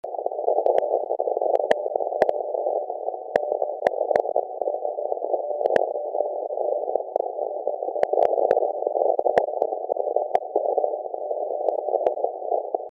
SAQ copy in CT USA maybe a bit below average signal strength. Frequency stability was not up to normal standards.